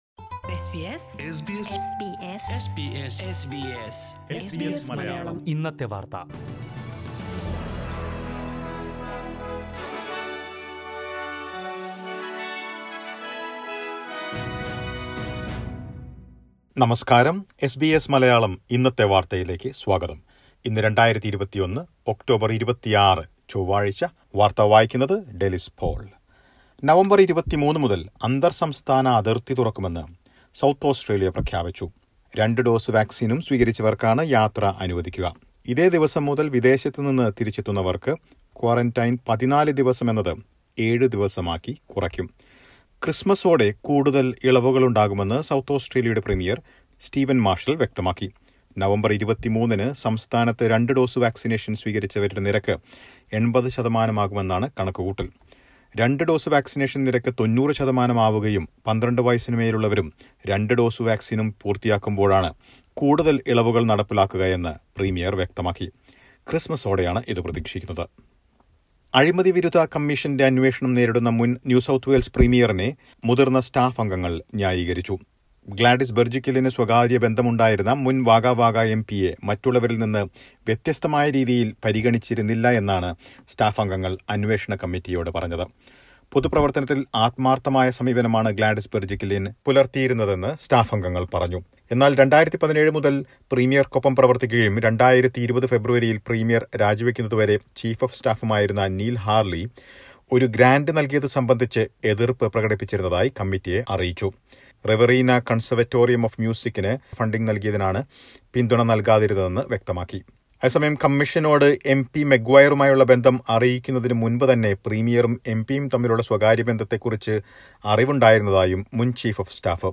2610_bulletin_edit.mp3